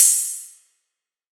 archived music/fl studio/drumkits/bvker drumkit/Cymbals/Rides